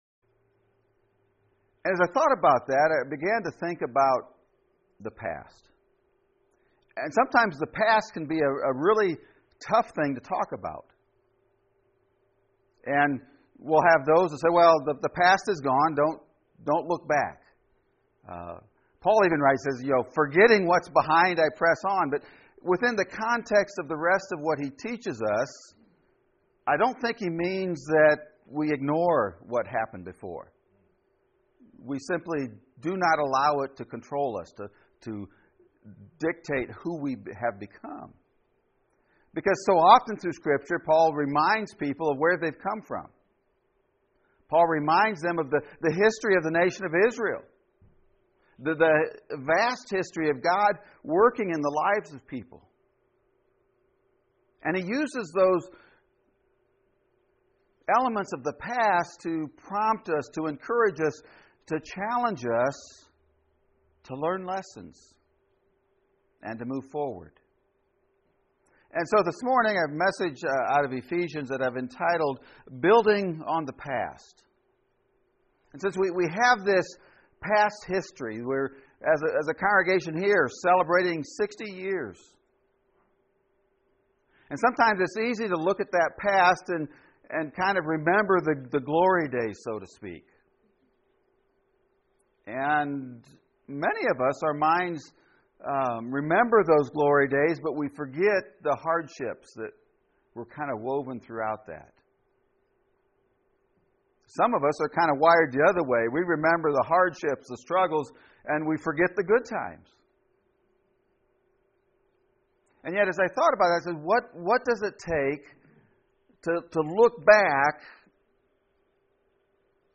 Building On the PAST! (Sermon Audio)